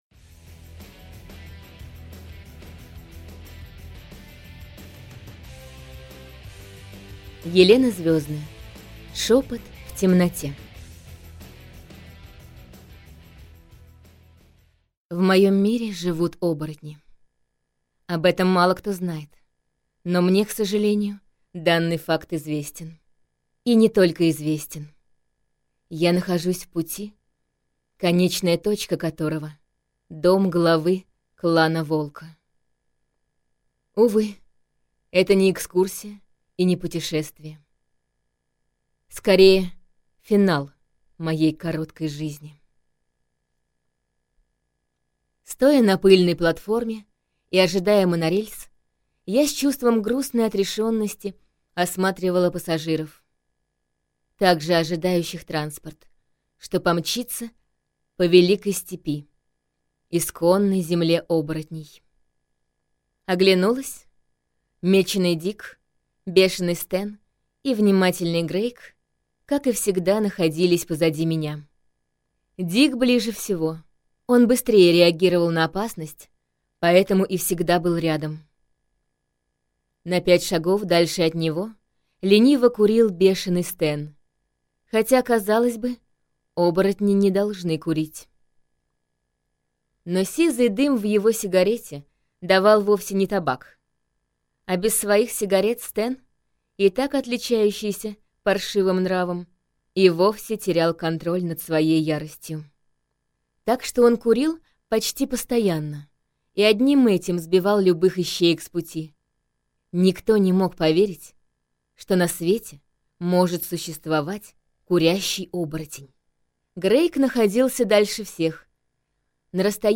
Аудиокнига Шепот в темноте - купить, скачать и слушать онлайн | КнигоПоиск